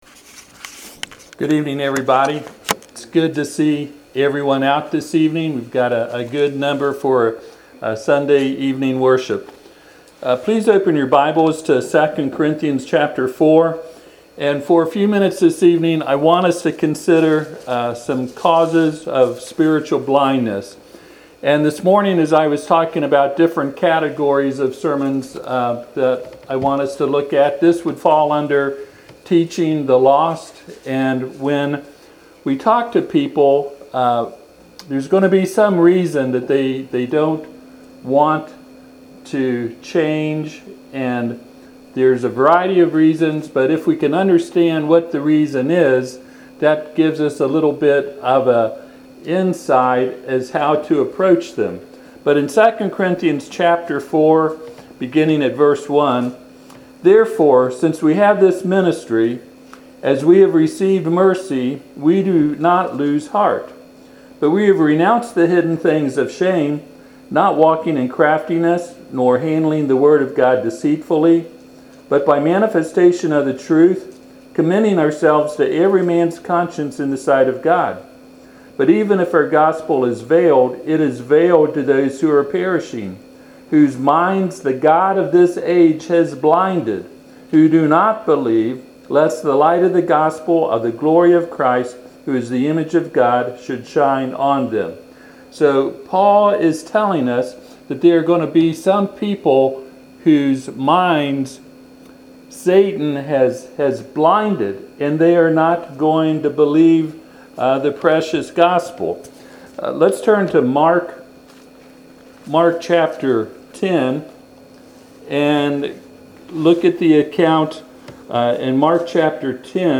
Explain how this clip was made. Passage: 2 Corinthians 4:1-4 Service Type: Sunday PM « Sermon on the Mount